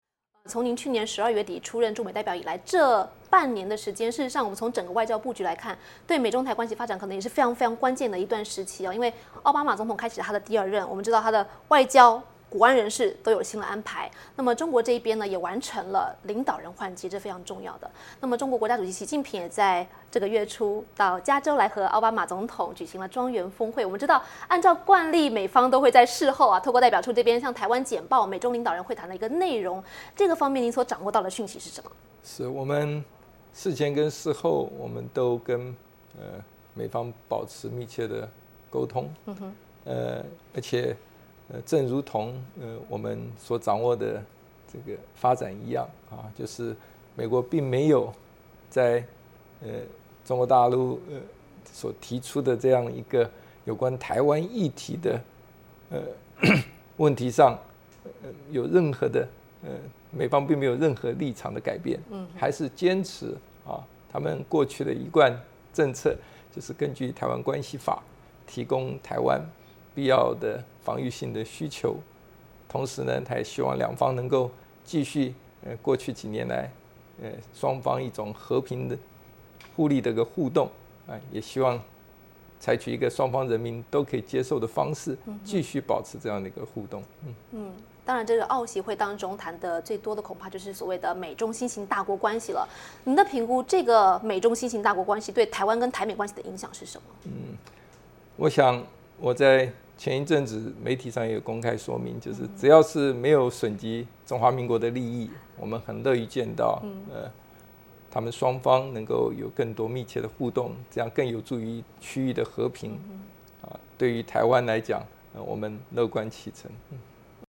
《海峡论谈》VOA专访节选: 台湾驻美代表金溥聪谈美中台非零和游戏